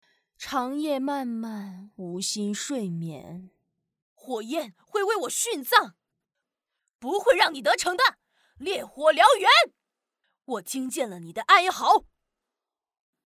女国146_动画_游戏_游戏御姐.mp3